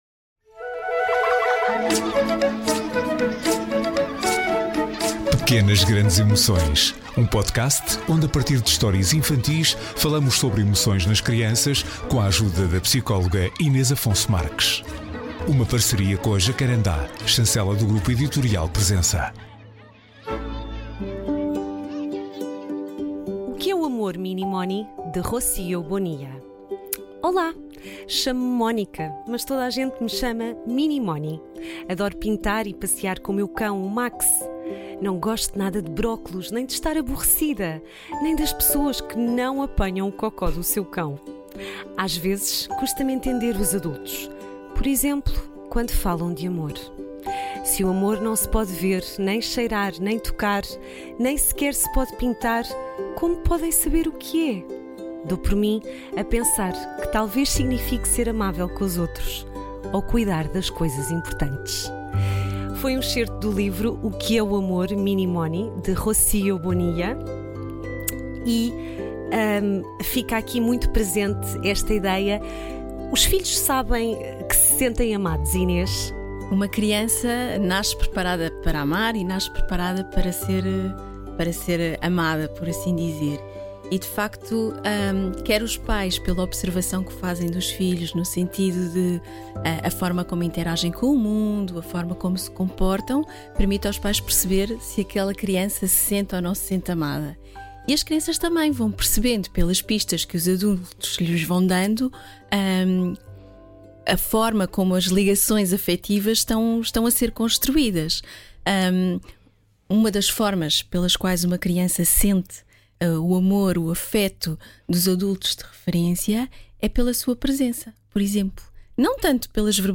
Nesta conversa